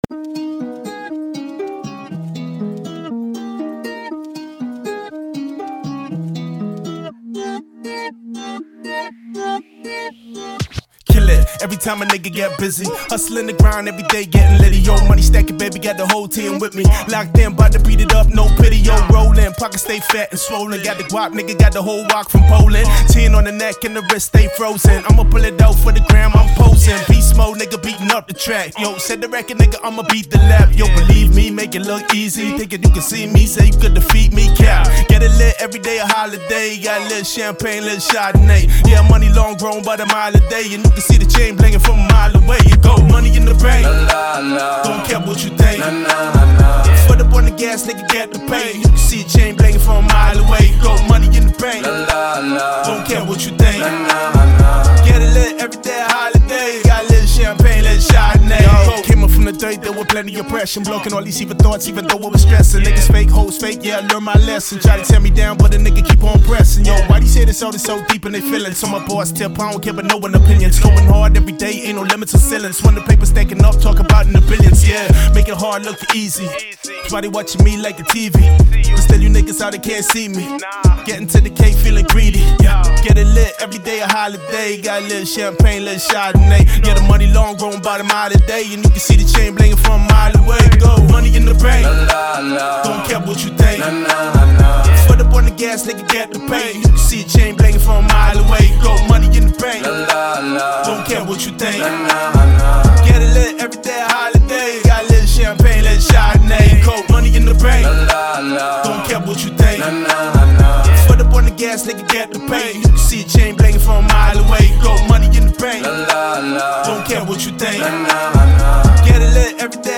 Fully mixed acapella